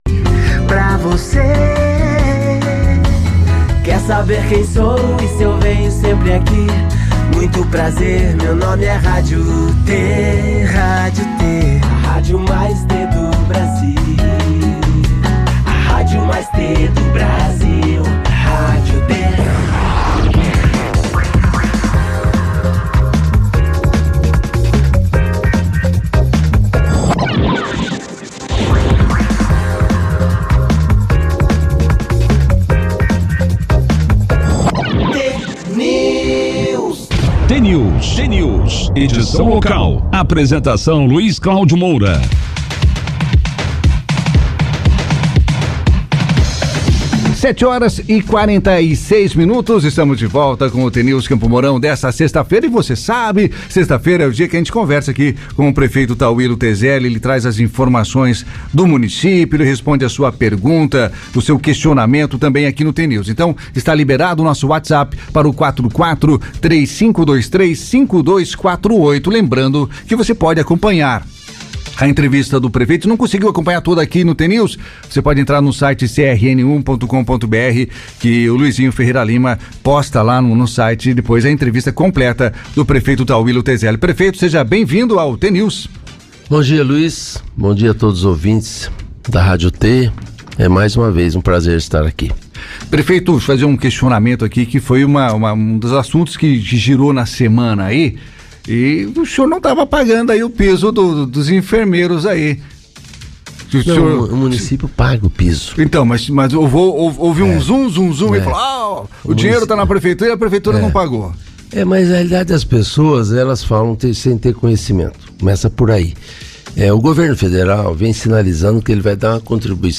Como faz já há mais de duas décadas, Tauillo Tezelli, atual prefeito de Campo Mourão, participou nesta sexta-feira, dia 25, do jornal T News, da Rádio T FM.